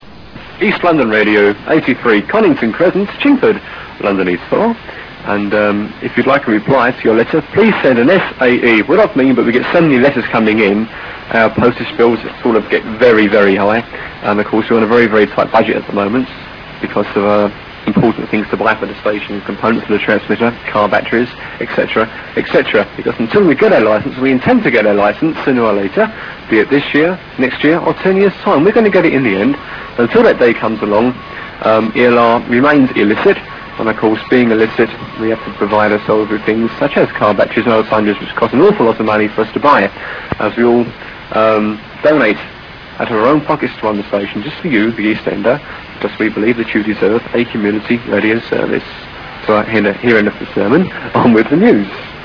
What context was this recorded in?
EAST LONDON RADIO 201 Metres Medium Wave The signal was always strong and the station built up a large following due to the emphasis on the community programming.